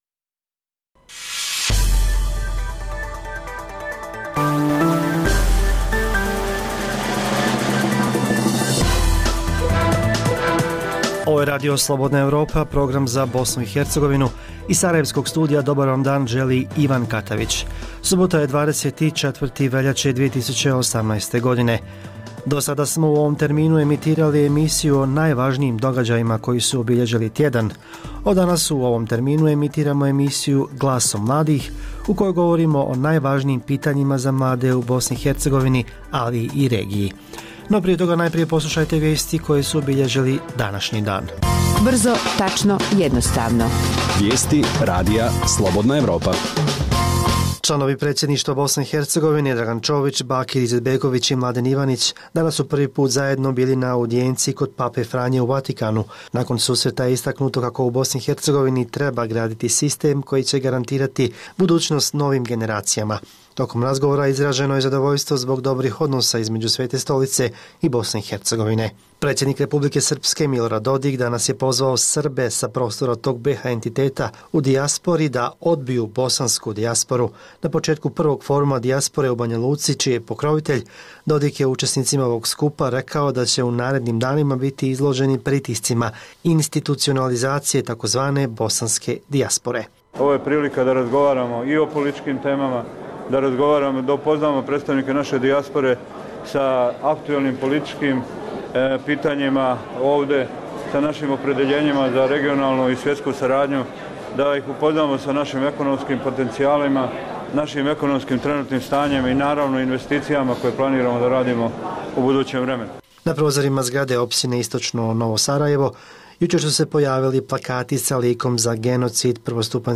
O problemu vršnjačkog nasilja razgovaramo s mladima u Beogradu, Zagrebu, Podogorici i Mostaru.